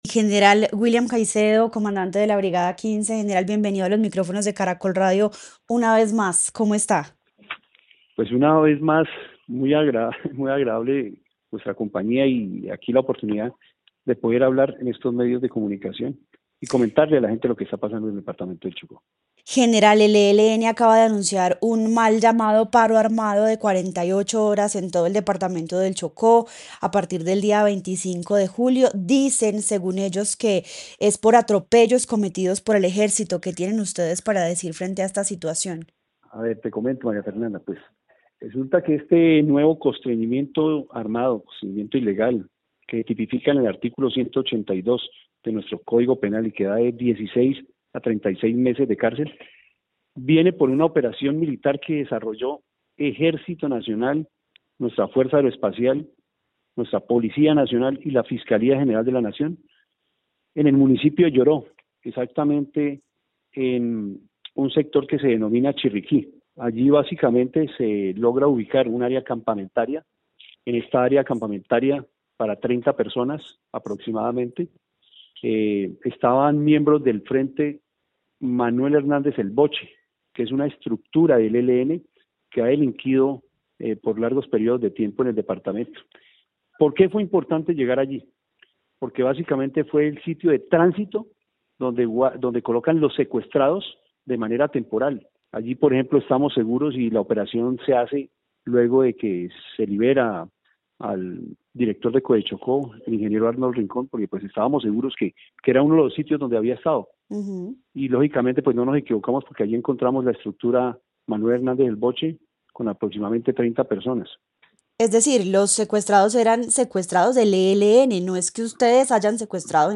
El general William Caicedo, comandante de la Brigada 15 del Ejército Nacional, confirmó en entrevista con Caracol Radio que una operación militar en el municipio de Lloró, Chocó, desencadenó el reciente anuncio de un paro armado de 48 horas por parte del ELN.